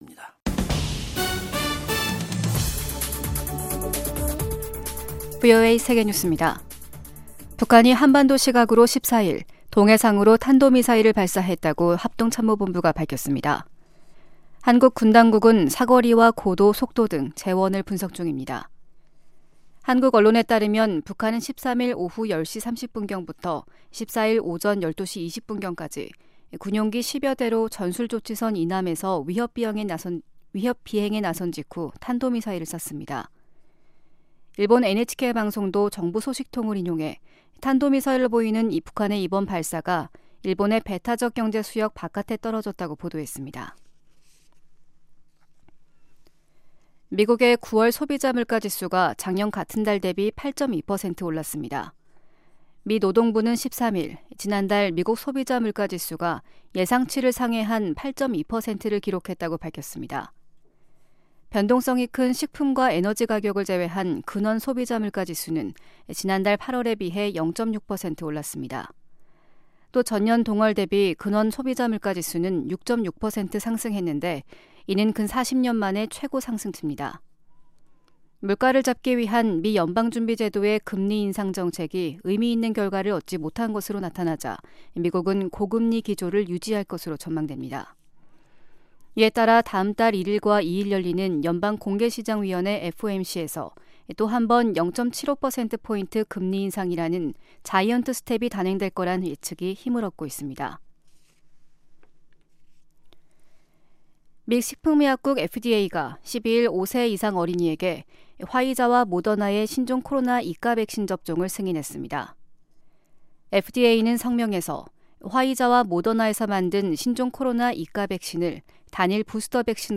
세계 뉴스와 함께 미국의 모든 것을 소개하는 '생방송 여기는 워싱턴입니다', 2022년 10월 14일 아침 방송입니다. '지구촌 오늘'에서는 러시아군이 우크라이나 주요도시들을 또 공습한 소식 전해드리고, '아메리카 나우'에서는 샌디훅 초등학교 총기난사 사건이 날조라고 주장해 온 극우 음모론자 알렉스 존스 씨에게 법원이 9억6천500만 달러 배상을 명령한 이야기 살펴보겠습니다.